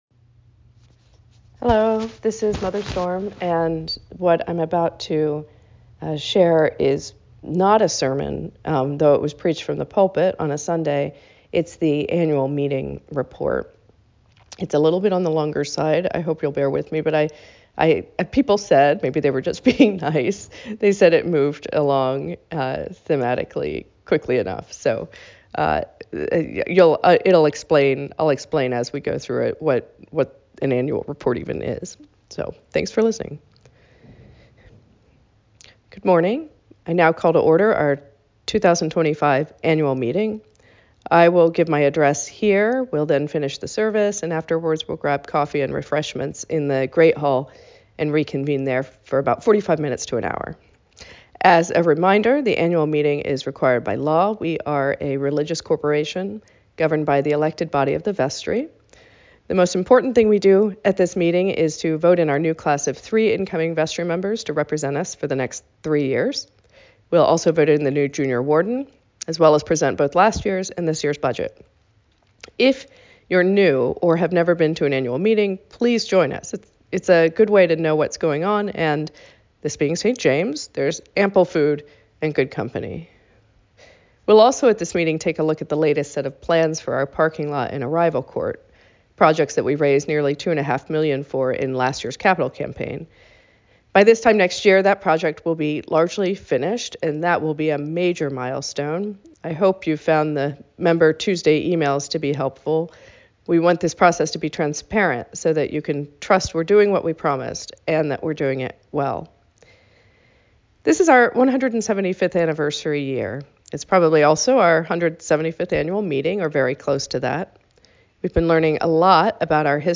Annual Meeting Address